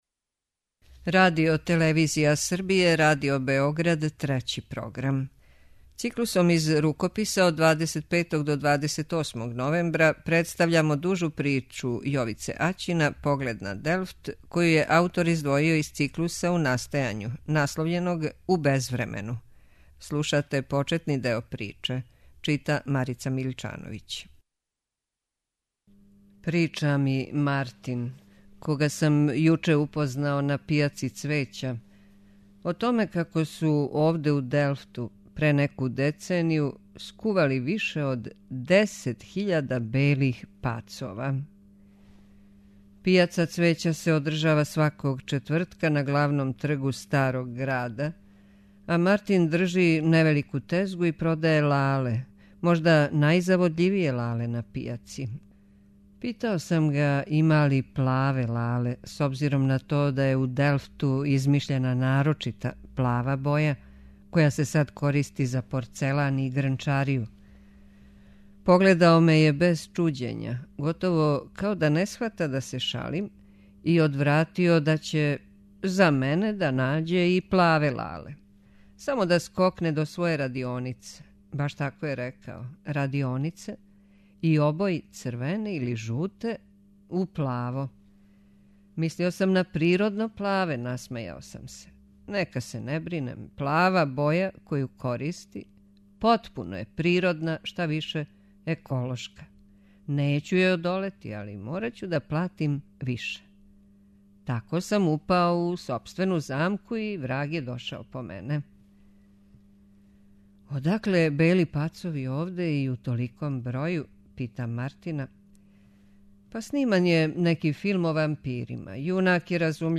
преузми : 36.35 MB Књига за слушање Autor: Трећи програм Циклус „Књига за слушање” на програму је сваког дана, од 23.45 сати.